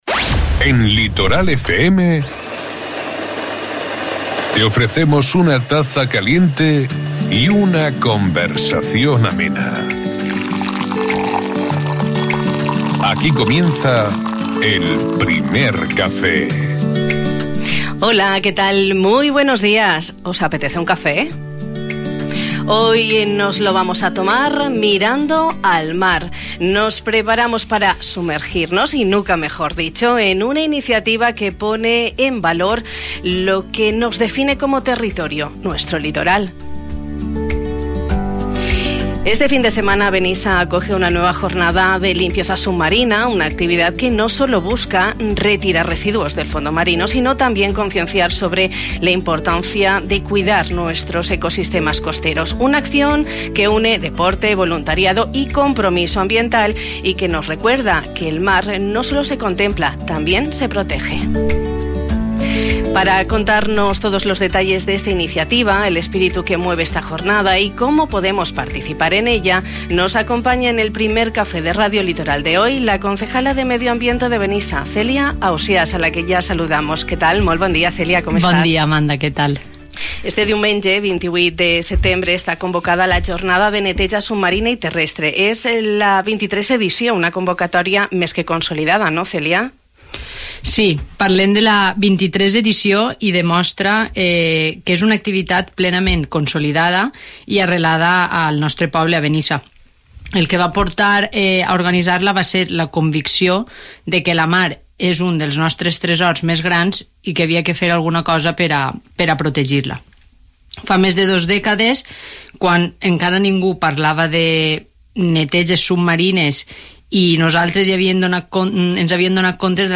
Celia Ausiàs, concejala de Medio Ambiente en el Ayuntamiento de Benissa.